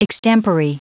Prononciation du mot extempore en anglais (fichier audio)
Prononciation du mot : extempore